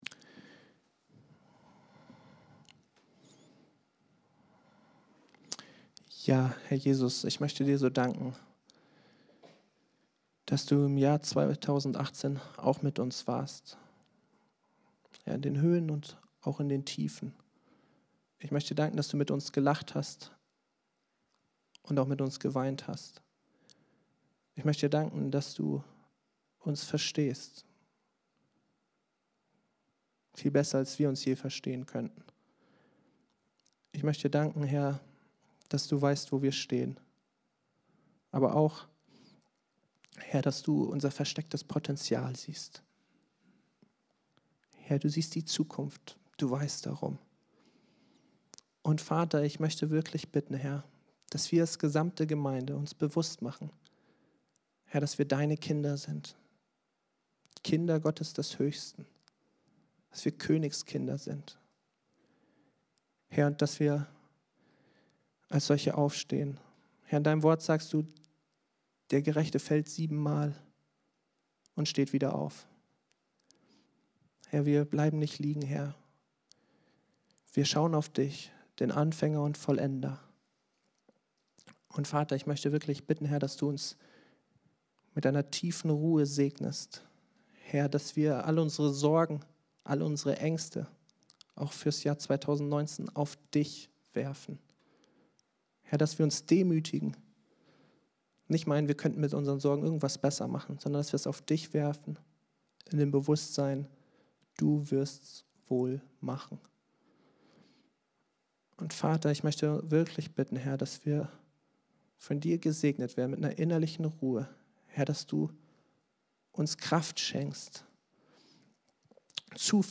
PREDIGTEN
Die Online-Präsenz der freien evangelischen Andreas-Gemeinde Osnabrück